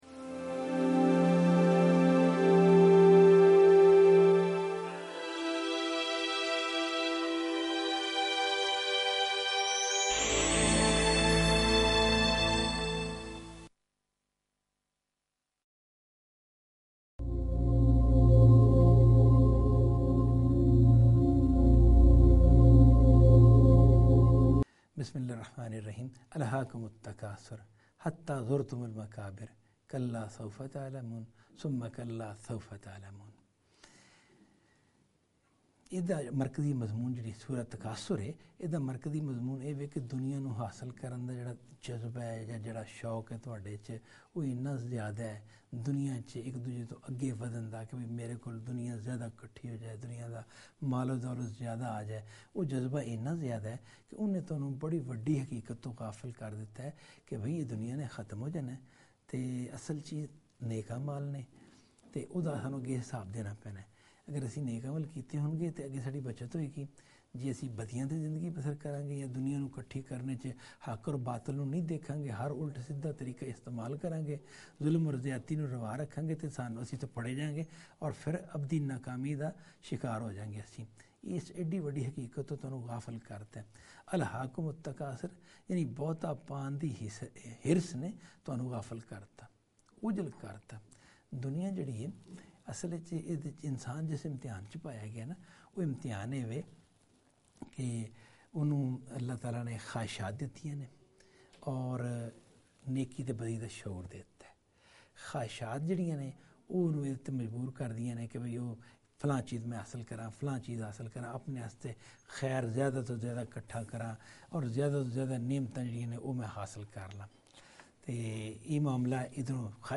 Qur'anic Surah Lecture series in Punjabi